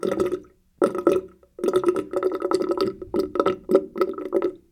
water-bathroom-02
bath bathroom bathtub bubble burp drain drip drop sound effect free sound royalty free Nature